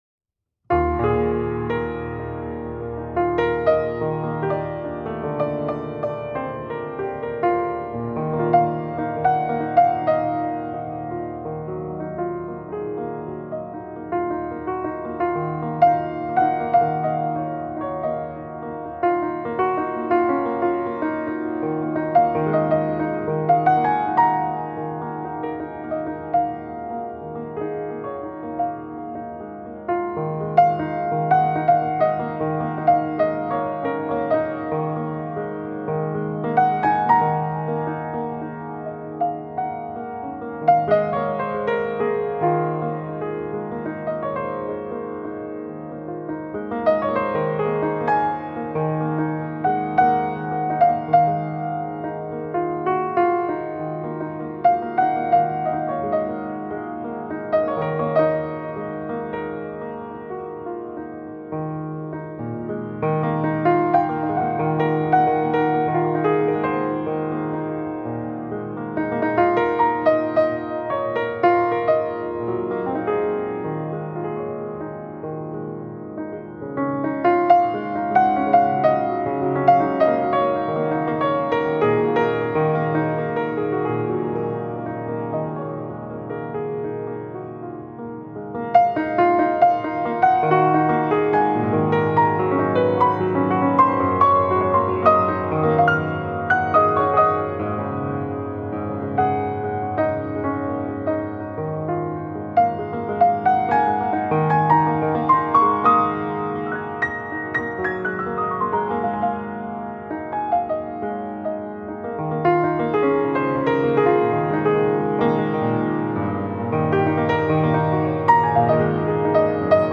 قطعة موسيقية
عزف على البيانو